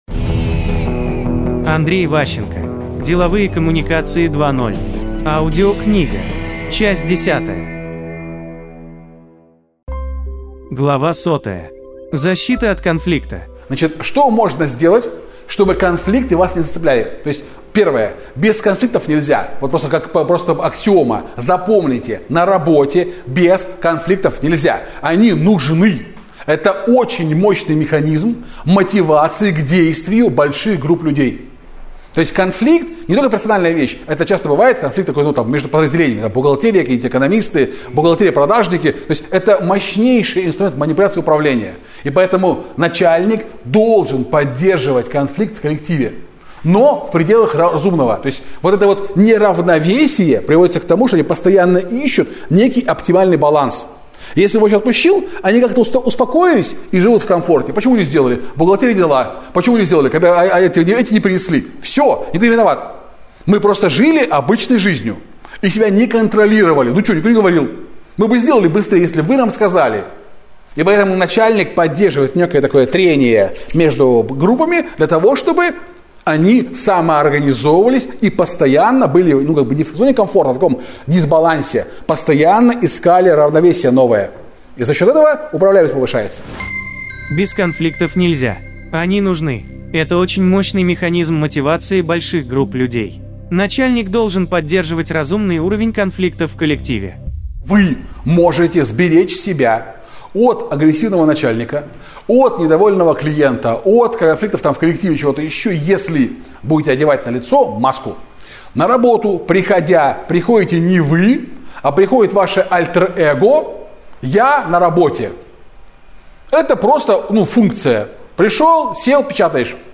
Аудиокнига Деловые коммуникации 2.0. Часть 10 | Библиотека аудиокниг